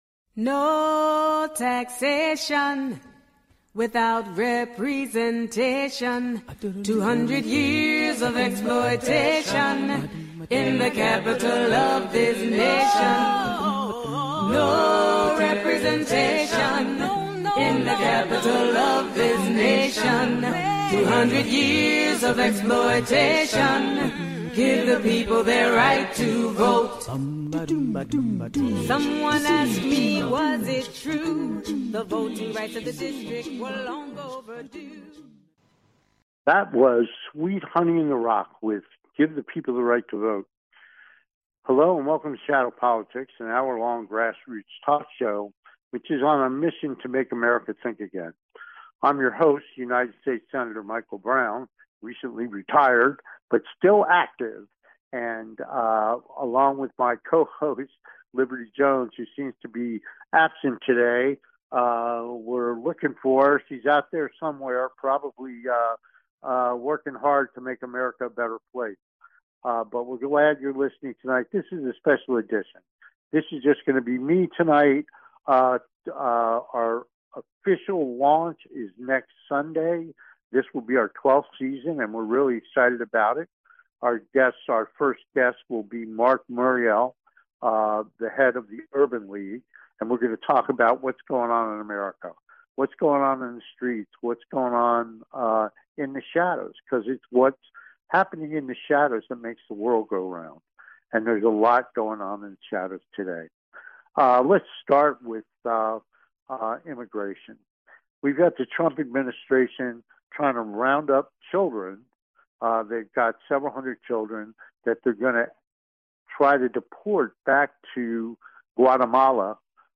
Shadow Politics is a grass roots talk show giving a voice to the voiceless.